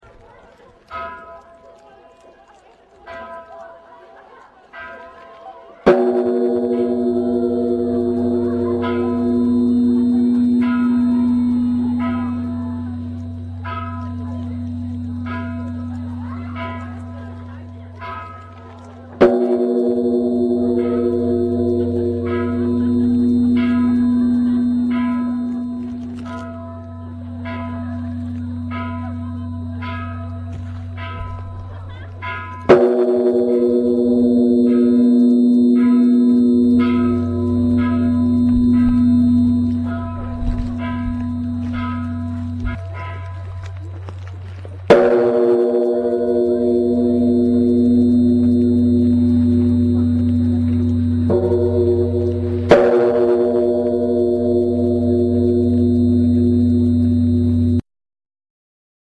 除夜の鐘（二戸市浄法寺町）
大晦日（おおみそか）の夜、煩悩を除き清浄な心身で新年を迎えるために、鐘を108回打ち鳴らします。日本の仏教寺院における年末の恒例行事です。